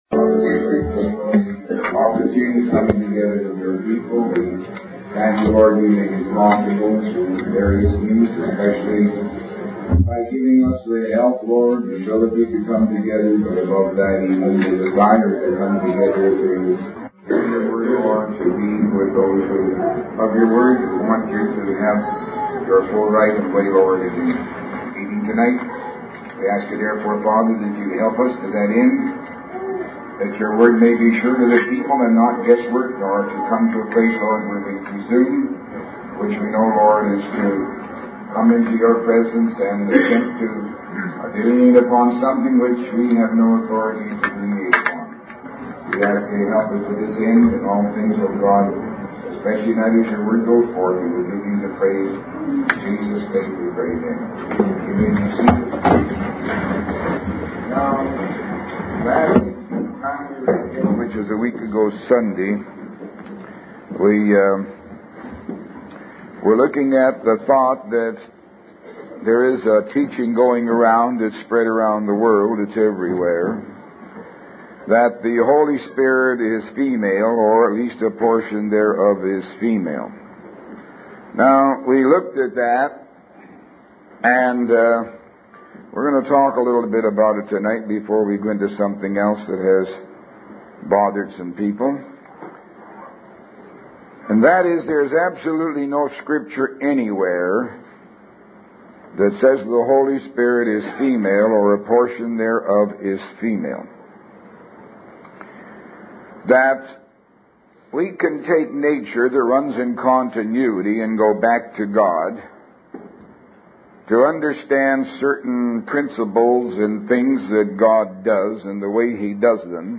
[Opening Prayer not very clear.]